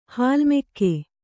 ♪ hālmekke baḷḷi